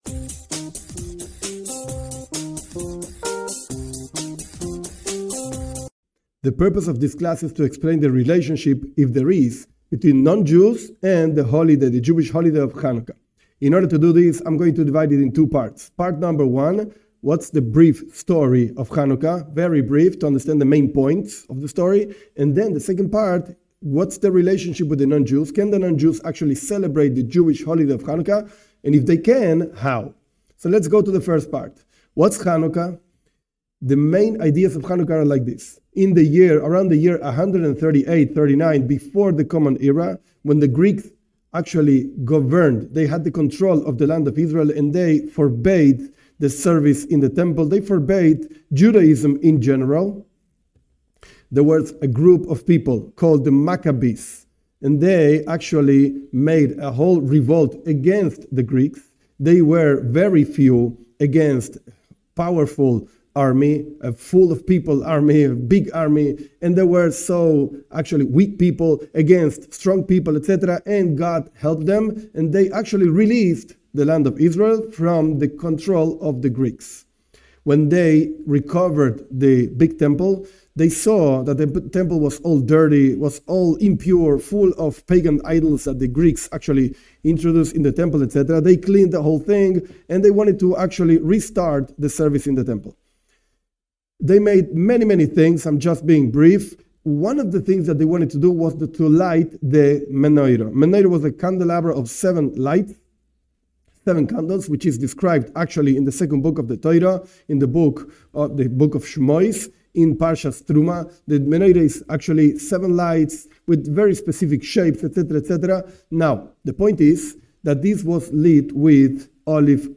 The purpose of this class is to explain the relationship between Bnei Noach and Chanukah. A brief review of the story and the practical application of the holiday for non-Jews.